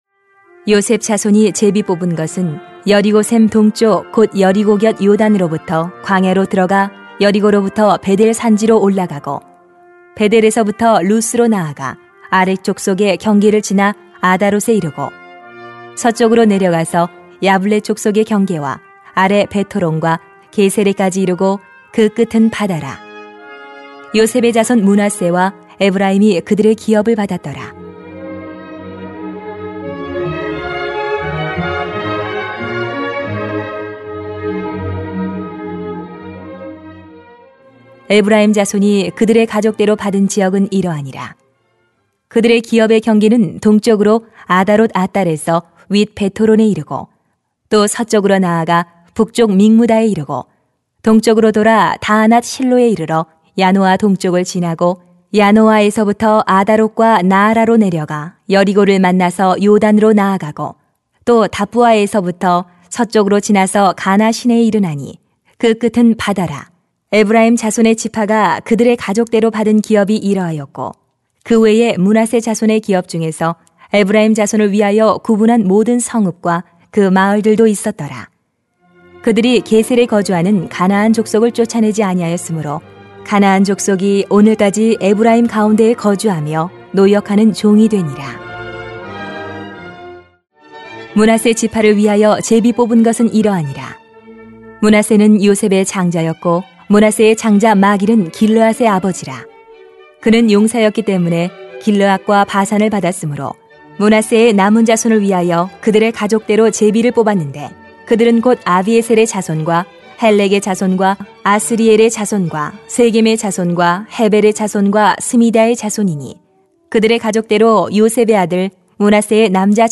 [수 16:1-17:6] 은혜를 이루는 하나님 나라 > 새벽기도회 | 전주제자교회